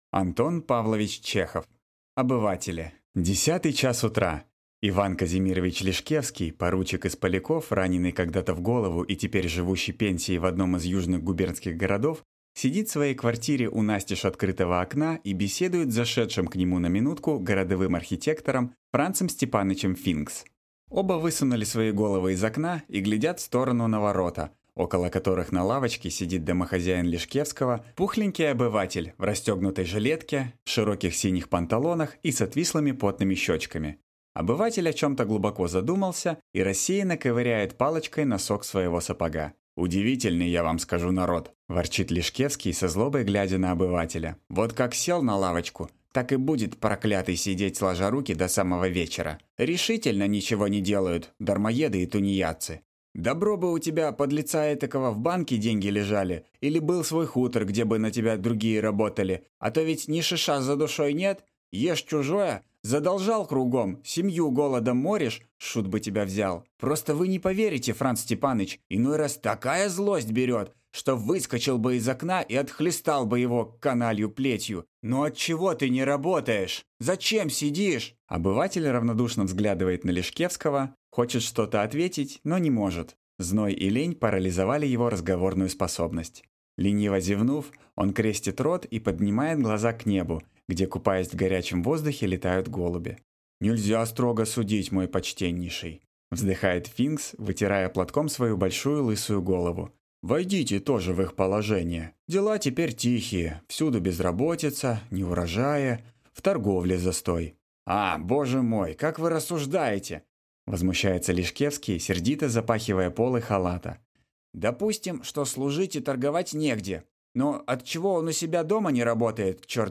Aудиокнига Обыватели